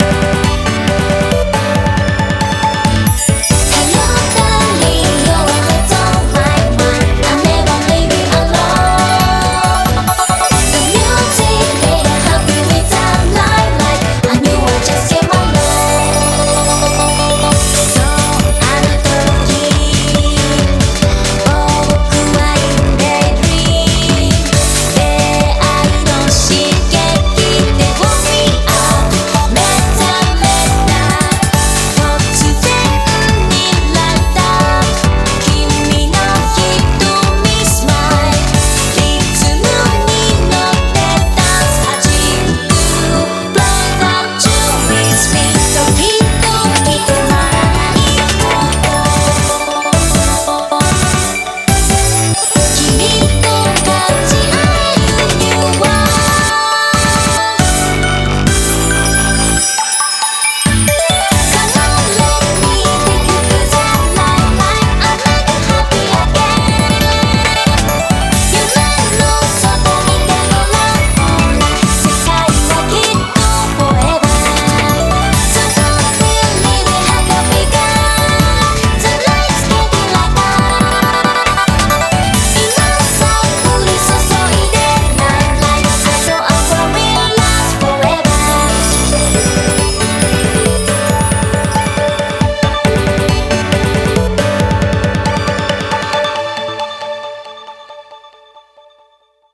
BPM137
Audio QualityPerfect (High Quality)
Cute song.